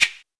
PERC.33.NEPT.wav